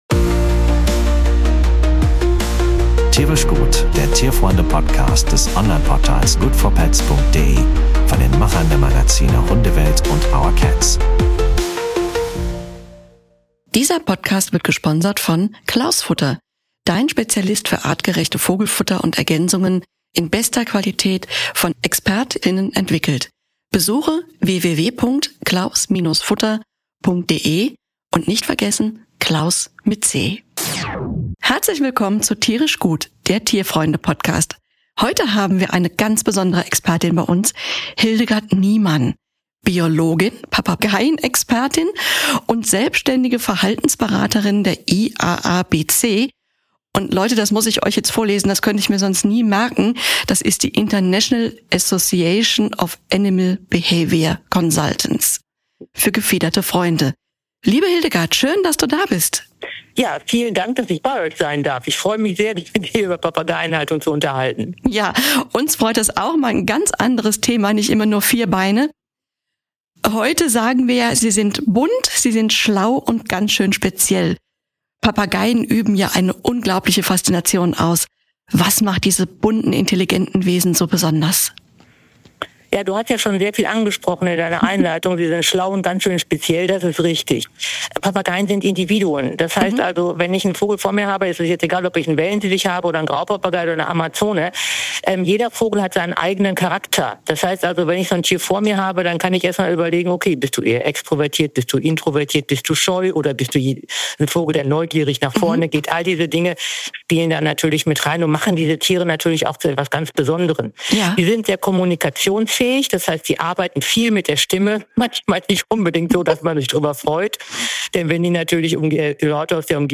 Papageien-Talk